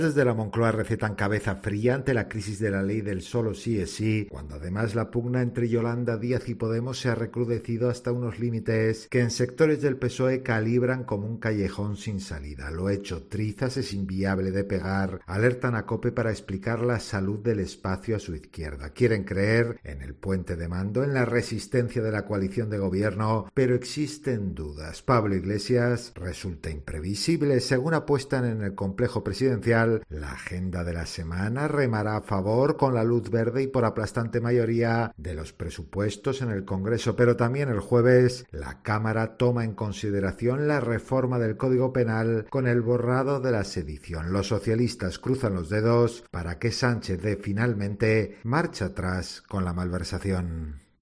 El PSOE en shock con la salud de la coalición, lo analiza